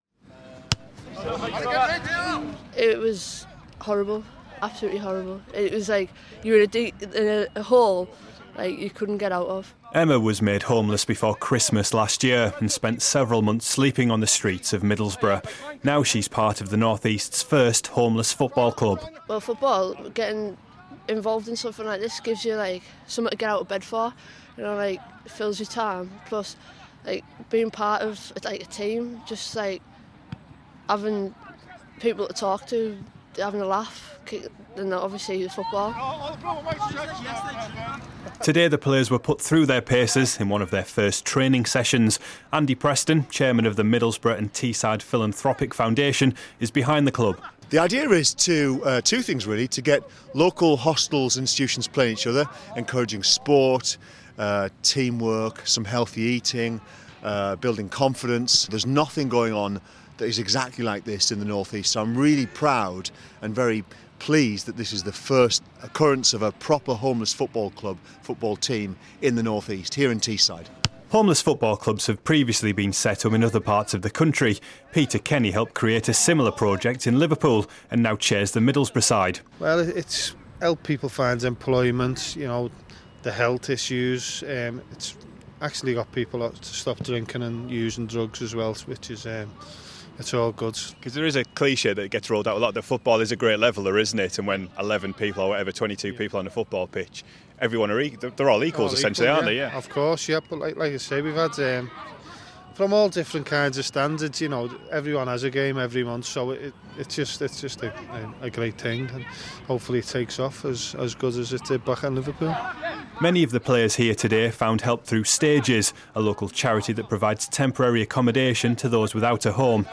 The first homeless football club in the North East has been launched on Teesside. I went along to see some of the players in action...